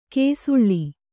♪ kēsuḷḷi